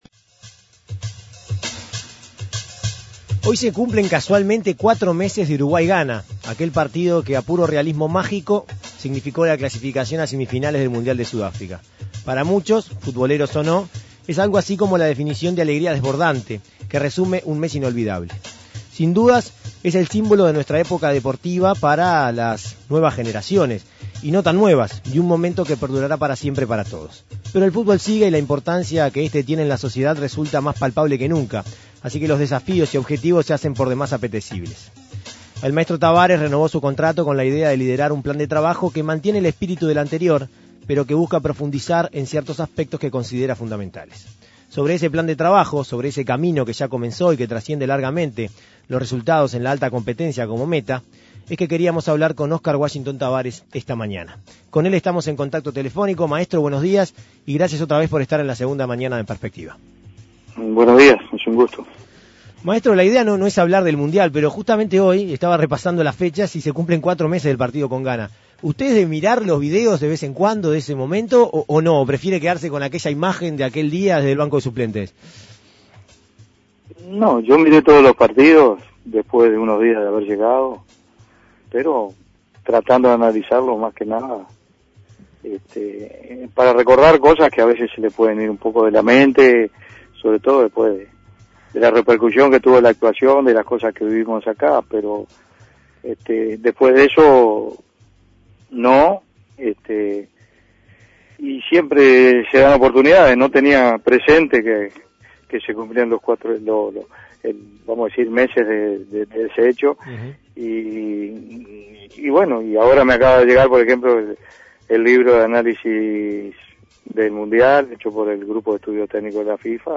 A escasos meses de Sudáfrica 2010, el maestro Tabárez conversó en la Segunda Mañana de En Perspectiva sobre su plan de trabajo para el fútbol uruguayo. Escuche la entrevista.